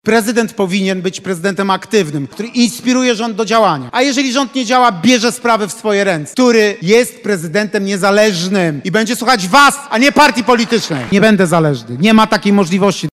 Po południu spotkał się z mieszkańcami Lubartowa.
– Będę inicjował działania rządu, a gdy ten nie będzie działał sprawnie, będę brał sprawy w swoje ręce – mówił w swoim wystąpieniu Rafał Trzaskowski.